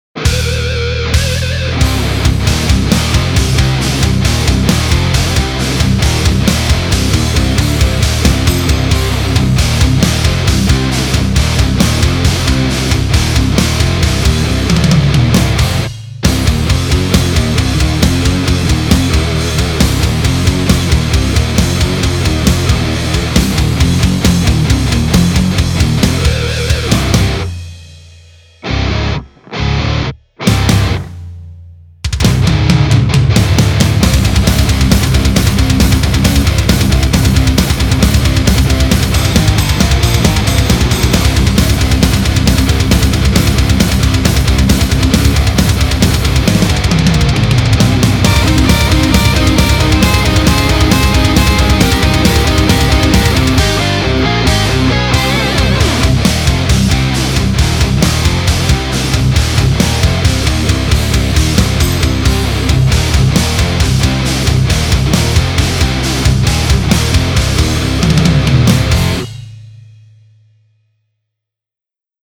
����� SVS TD III Tube Distortion